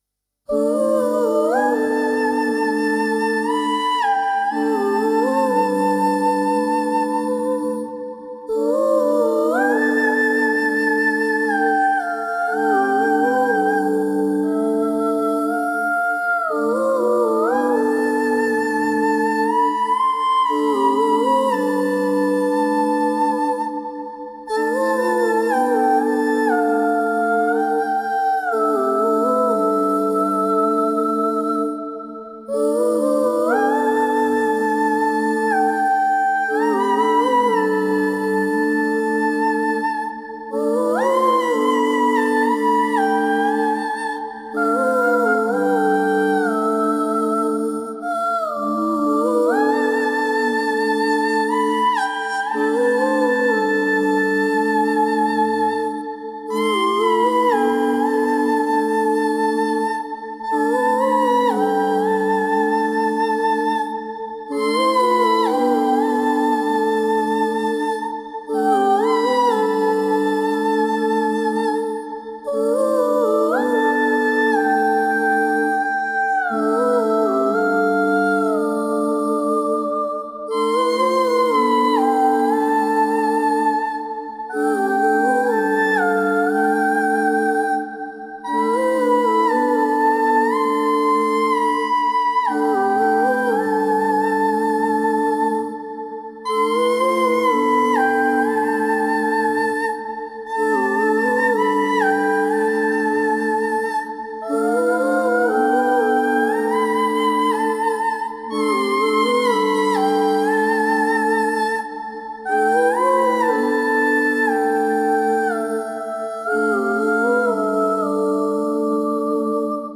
Tempo 120 BPM
Fantasy